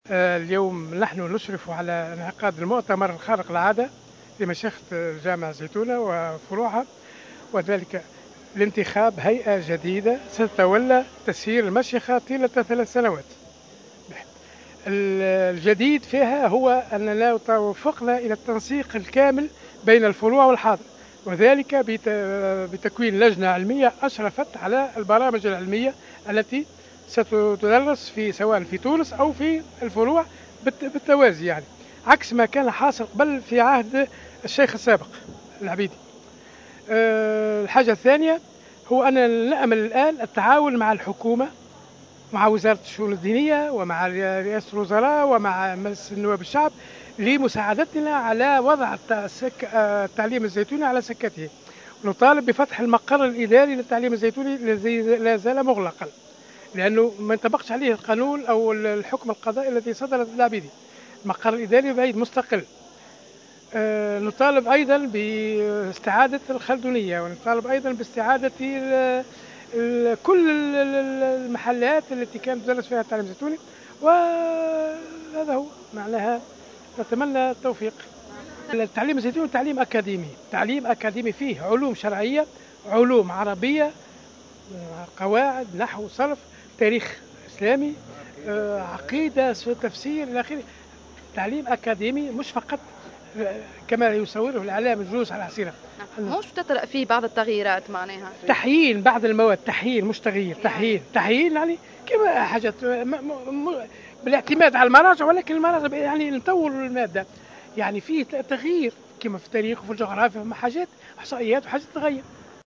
في تصريح لمراسلتنا في تونس على هامش هذا المؤتمر